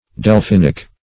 Delphinic \Del*phin"ic\, a. [See Delphin, n.] (Chem.)